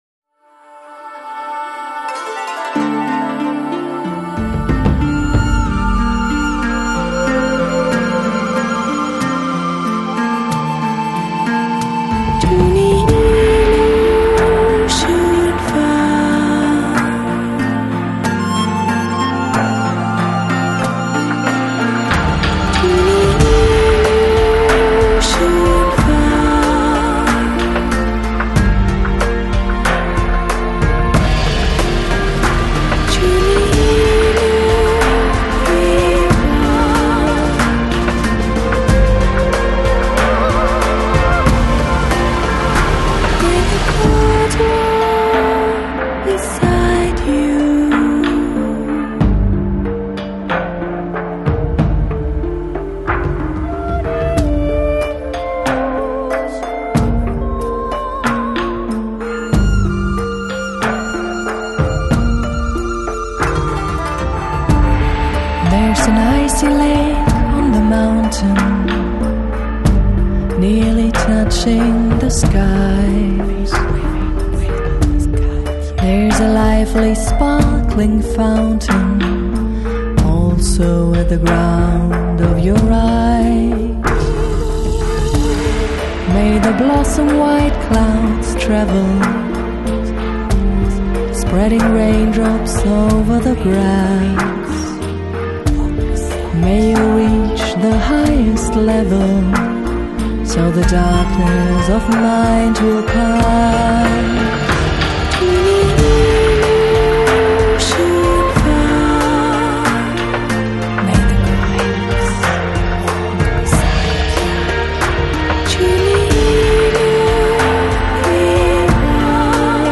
Electronic, Lounge, Chill Out, Downtempo